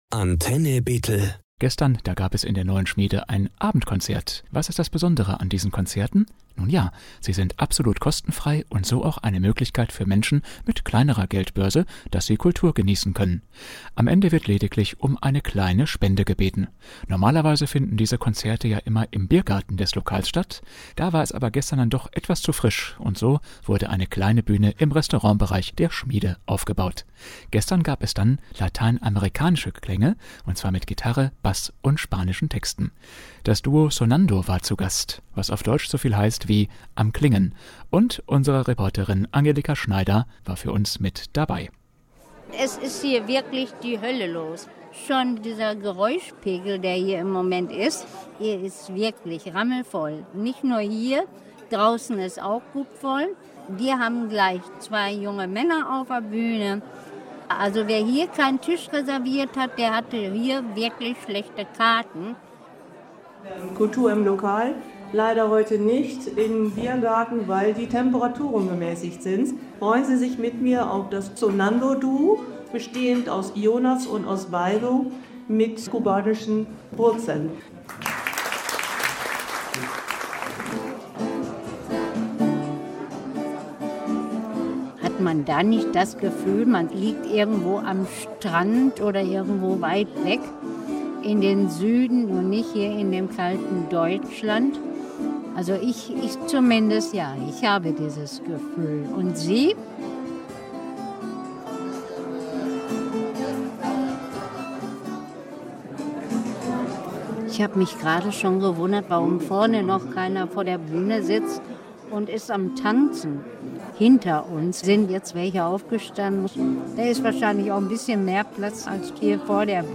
Kubanische Klänge waren am vergangenen Wochenede in der Neuen Schmiede zu hören mit latein-amerikanischen Rhythmen und Texten.
Reportage-Sonando.mp3